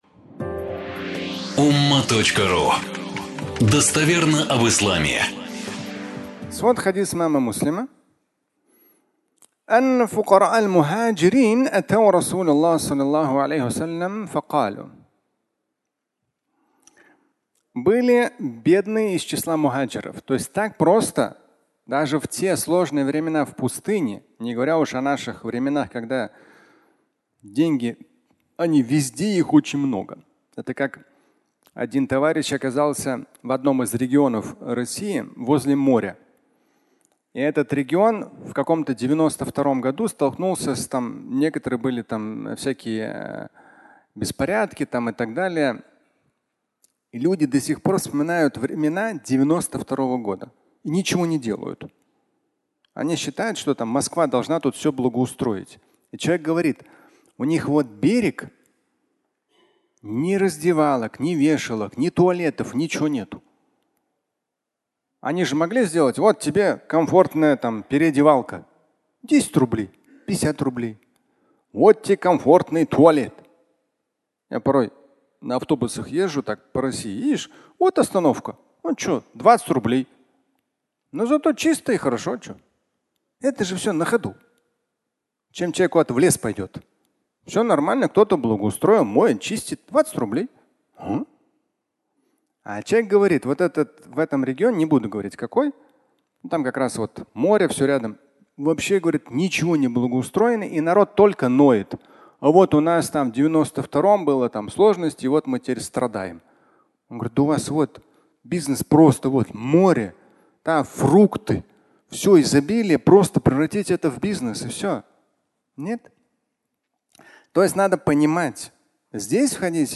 Богатый и бедный (аудиолекция)